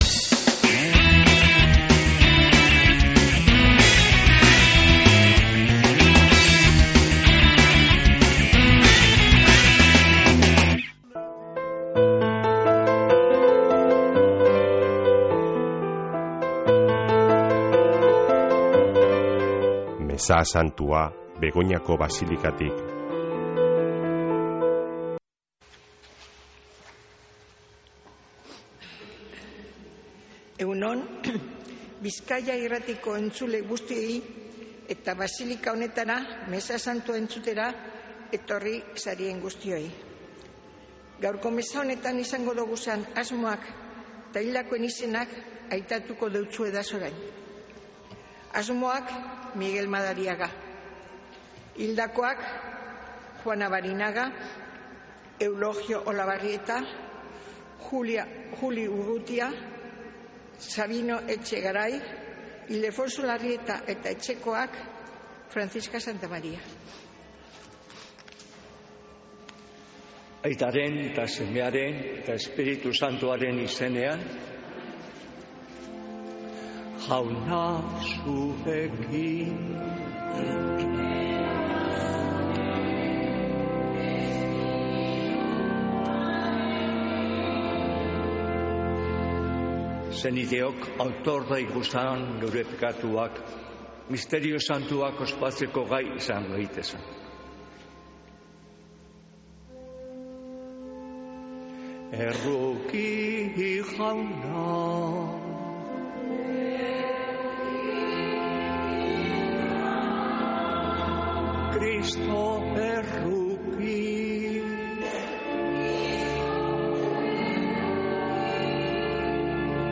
Mezea Begoñako basilikatik | Bizkaia Irratia